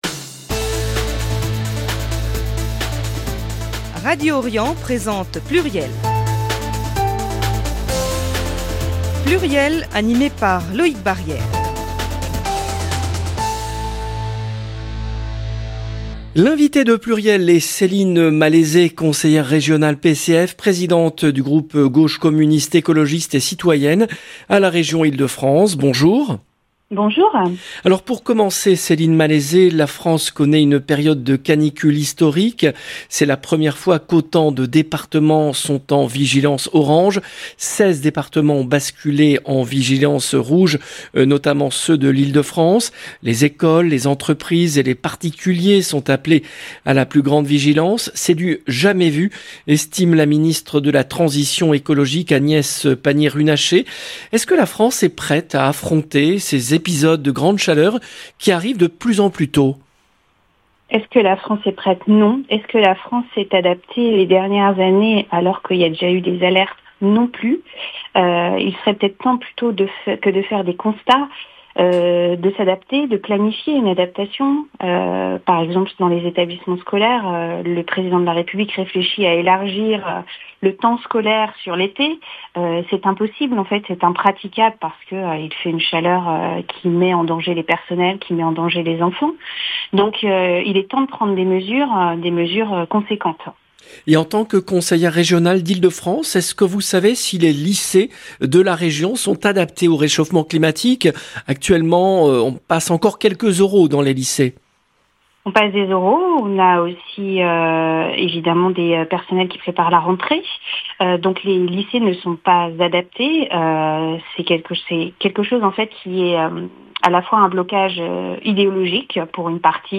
L’invitée de PLURIEL est Céline Malaisé, conseillère régionale PCF, présidente du groupe Gauche communiste écologiste et citoyenne à la Région Ile-de-France.